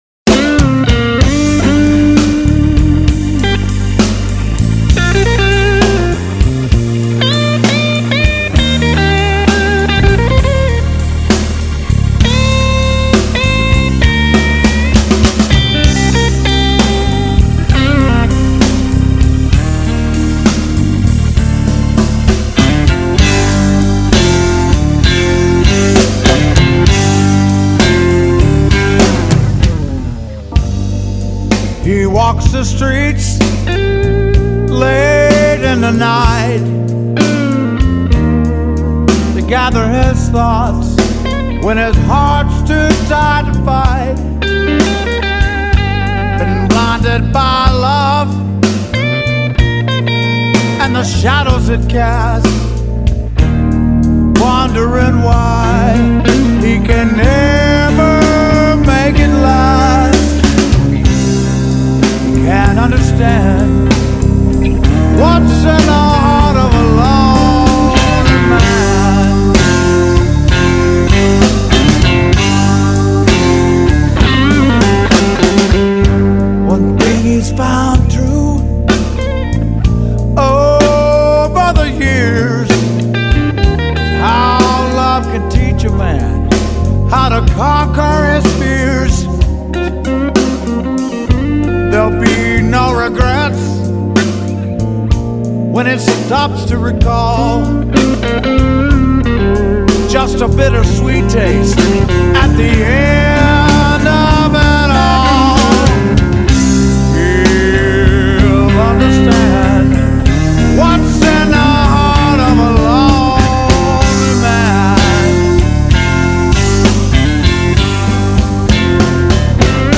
블루스